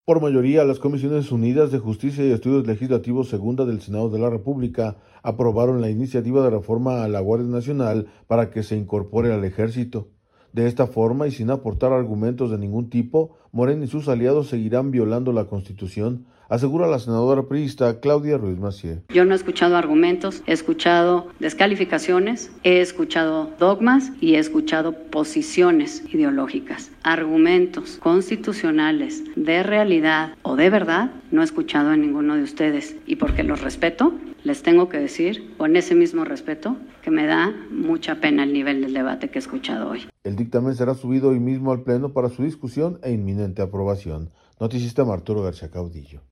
Por mayoría, las comisiones unidas de Justicia y Estudios Legislativos Segunda del Senado de la República, aprobaron la iniciativa de reforma a la Guardia Nacional para que se incorpore al Ejército. De esta forma y sin aportar argumentos de ningún tipo, Morena y sus aliados seguirán violando la Constitución, asegura la senadora priísta Claudia Ruíz Massieu.